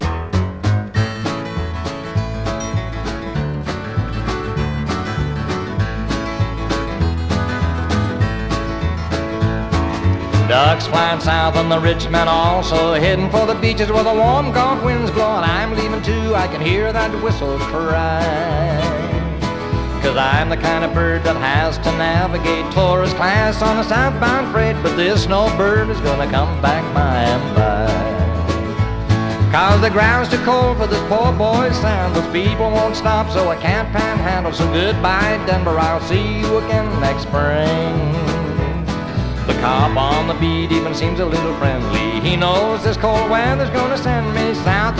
Country, Rock, World　USA　12inchレコード　33rpm　Stereo